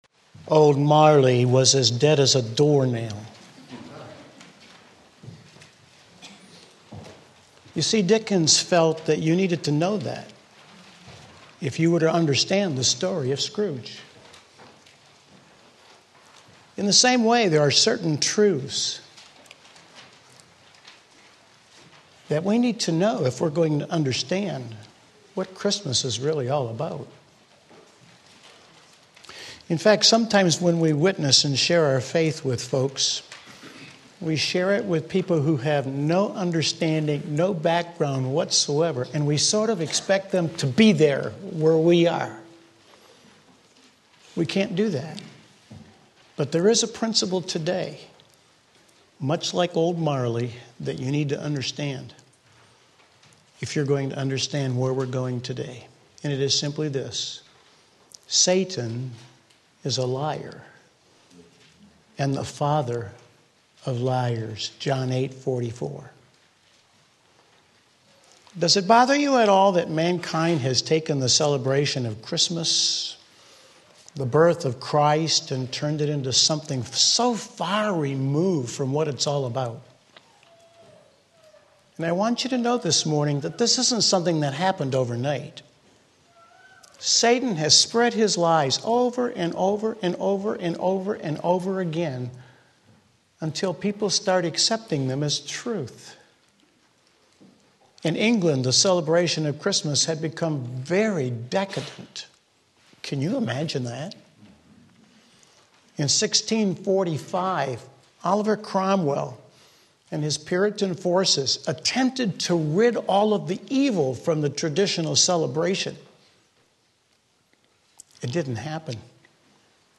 Sermon Link
Christ's Coming and Tradition Mark 7:1-13 Sunday Morning Service